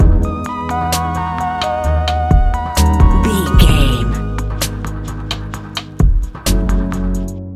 Ionian/Major
C♯
chilled
laid back
Lounge
sparse
new age
chilled electronica
ambient
atmospheric
morphing